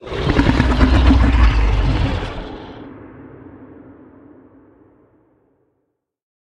File:Sfx creature bruteshark callout 05.ogg - Subnautica Wiki
Sfx_creature_bruteshark_callout_05.ogg